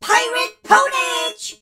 darryl_kill_vo_04.ogg